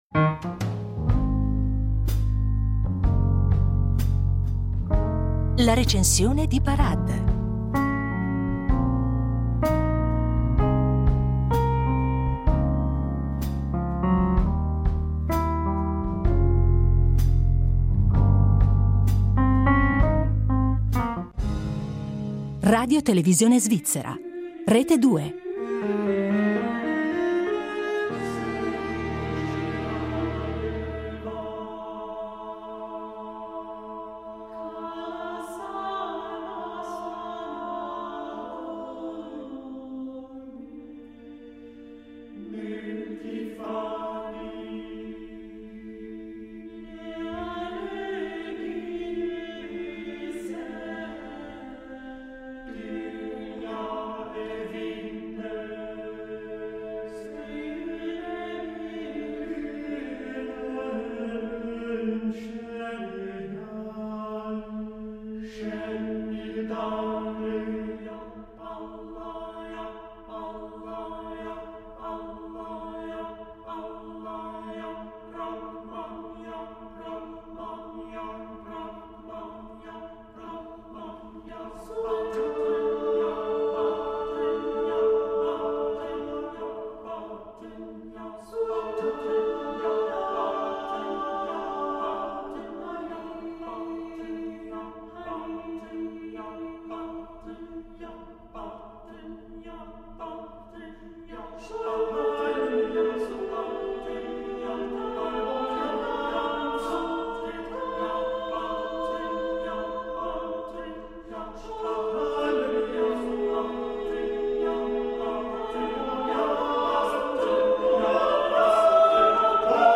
Nella prima, commissionata lo scorso anno dall’ensemble vocale e intitolata “The Triumph”, il gruppo è accompagnato da strumenti tradizionali turchi suonati dal collettivo DÜNYA formato da Sanlıkol per sviluppare la sua eclettica idea di linguaggio musicale cosmopolita.
La seconda composizione esclusivamente vocale composta nel 2017 è intitolata “Devran”, ed è basata sui versi di due inni devozionali trascritti dal poligrafo di origine polacca Ali Ufki al servizio della corte ottomana del XVI secolo, ed è concepita come un mottetto che richiama la tradizione rinascimentale europea.